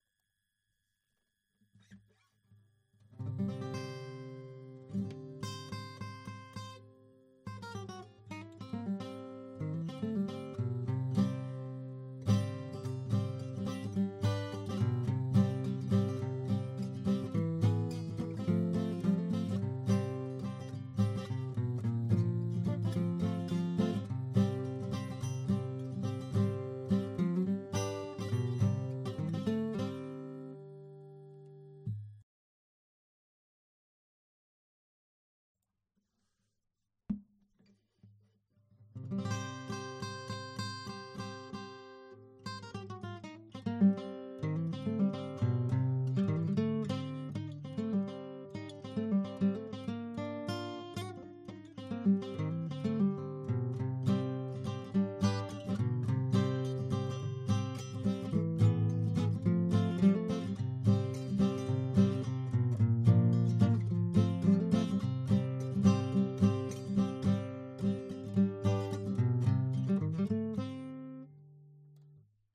Test ADA8200 vs RME babyface GUITARE.mp3
test-ada8200-vs-rme-babyface-guitare.mp3